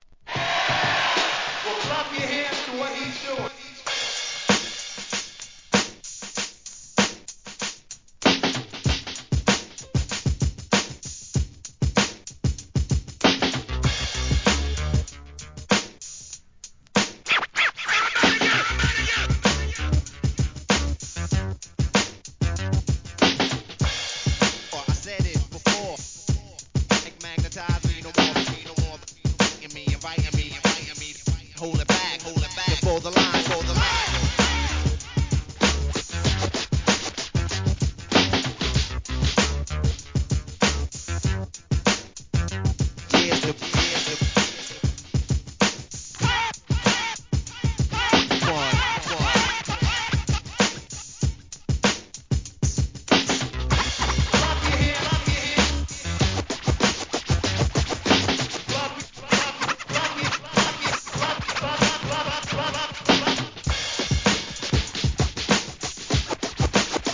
HIP HOP/R&B
を終始ガシガシ擦るVERSIONです!!
Extended Dub Version